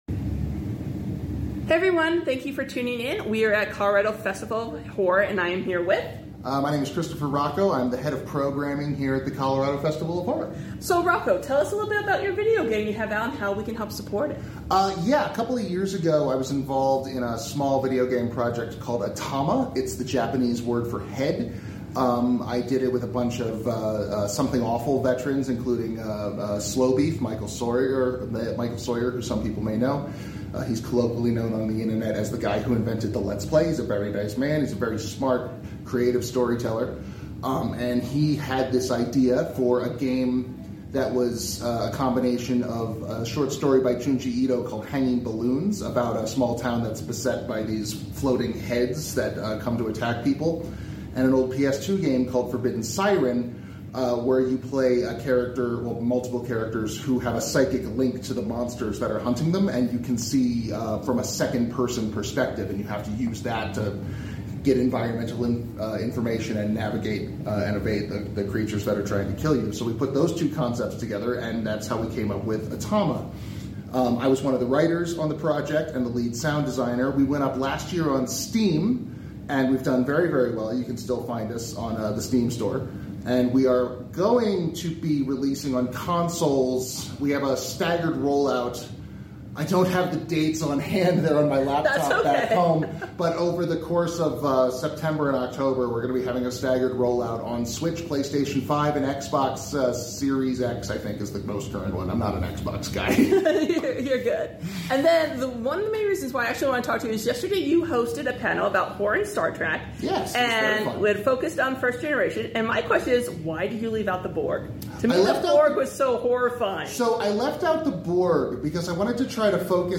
This was a quick interview sound effects free download